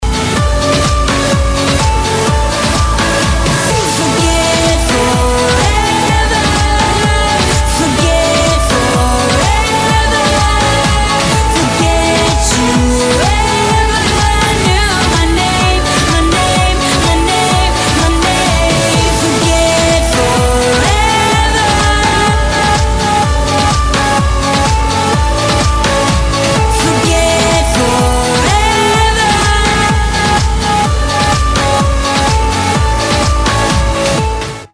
• Качество: 192, Stereo
поп
женский вокал
dance
club